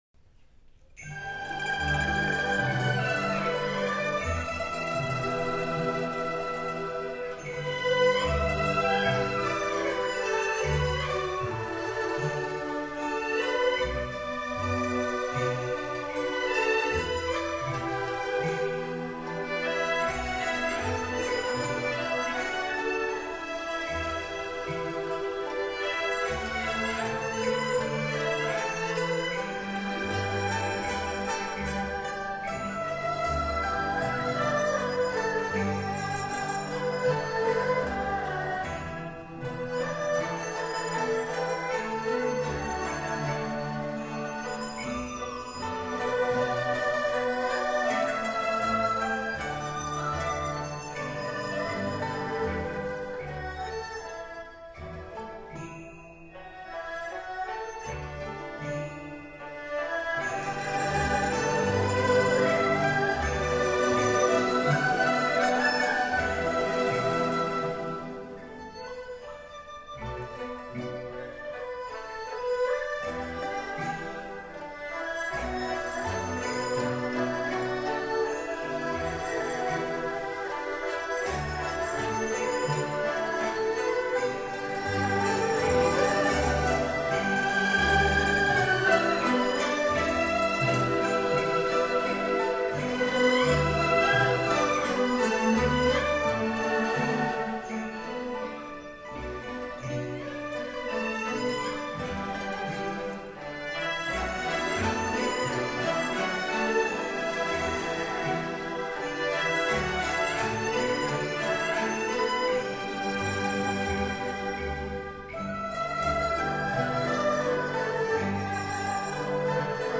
音乐类型: 民乐